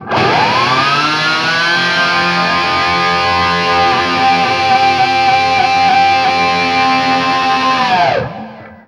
DIVEBOMB11-L.wav